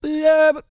E-CROON 3046.wav